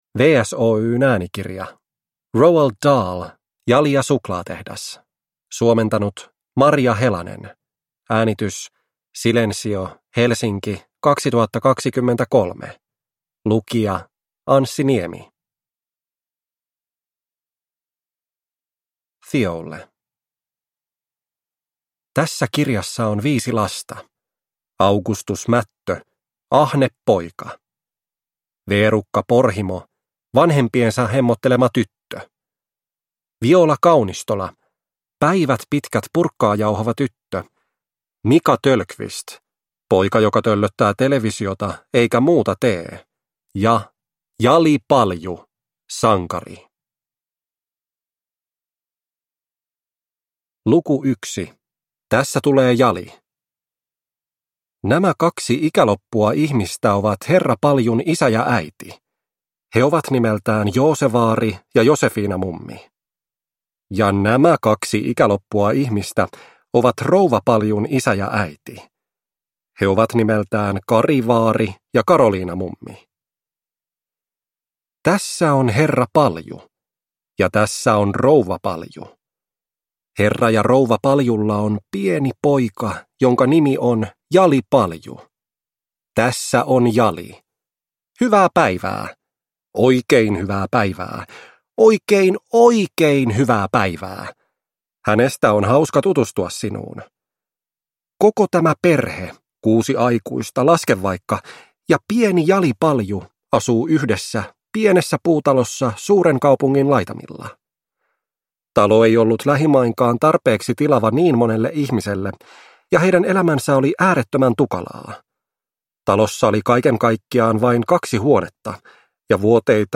Jali ja suklaatehdas – Ljudbok